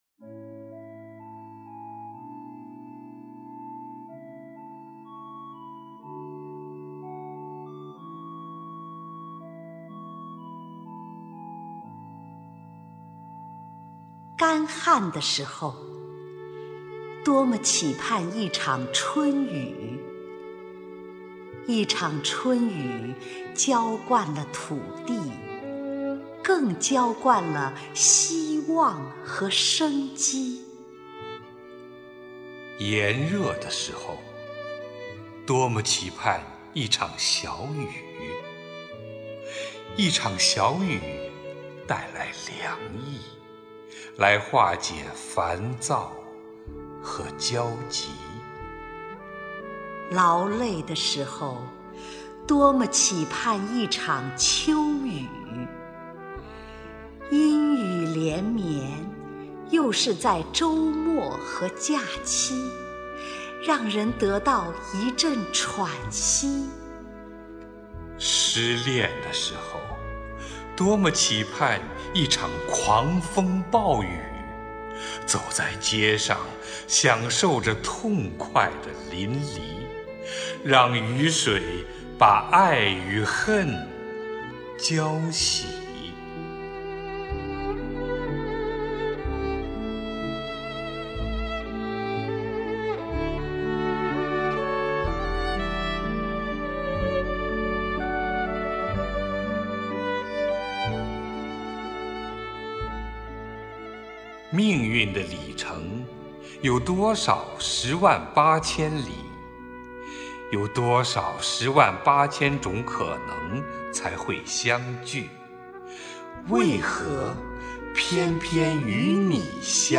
[21/10/2009]乔榛 丁建华朗诵著名诗人南枫的作品《雨绵绵 心霏霏_为何偏偏遇上你_你的美丽让我多情》
朗诵 乔榛 丁建华